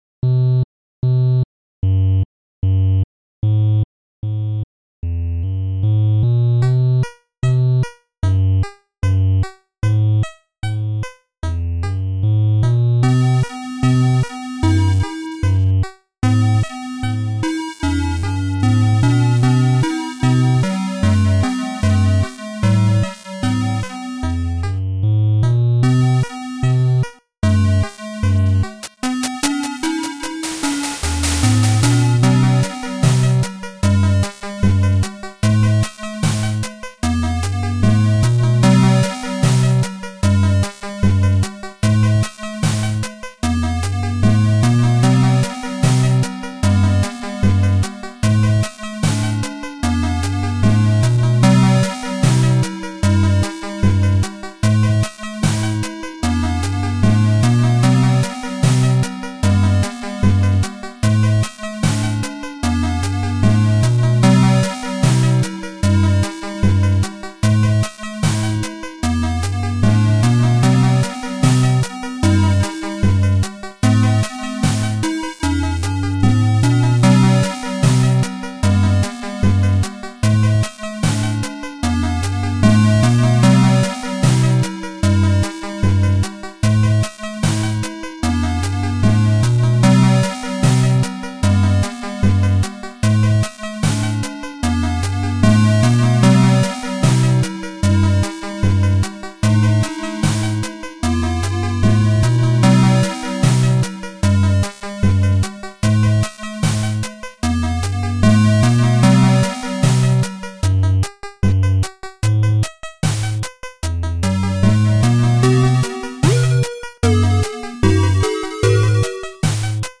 8 bit version